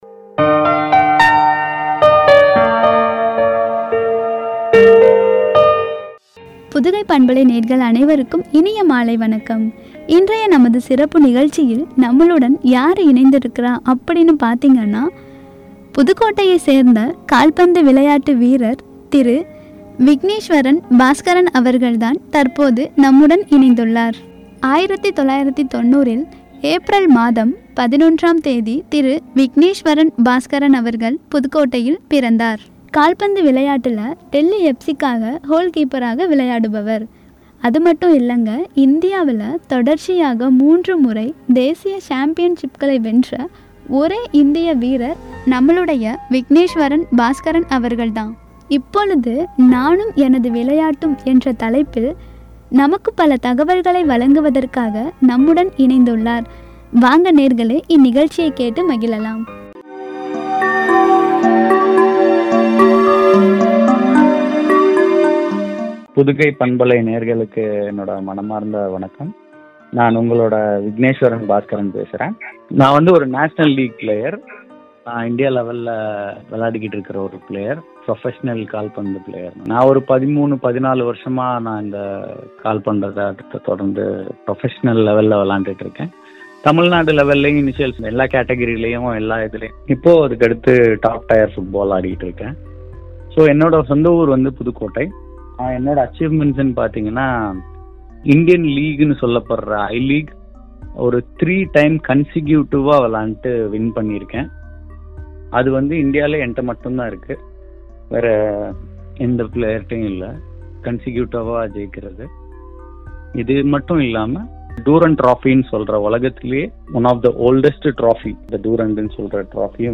எனது விளையாட்டும்” எனும் தலைப்பில் வழங்கிய உரை.